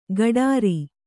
♪ gaḍāri